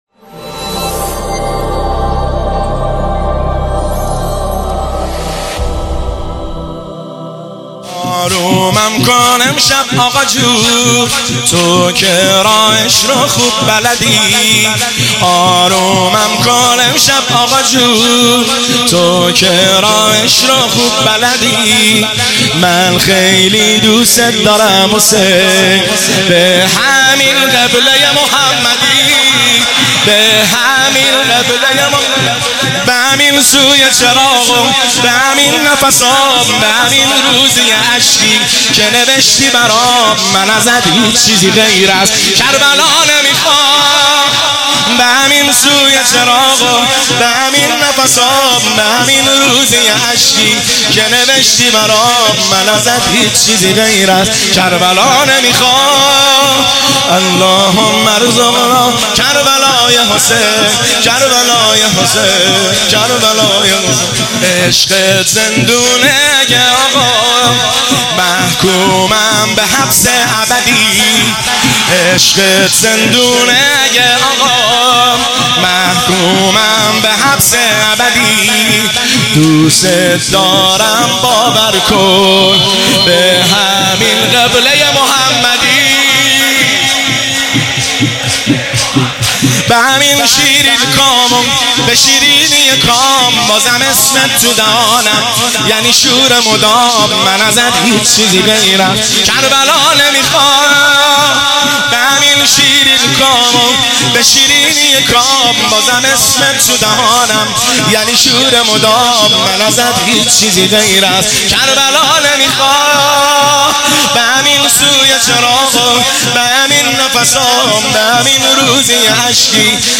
آرومم کن امشب آقاجون تو که راهش رو خوب بلدی شور – شب سوم ایام مسلمیه 1398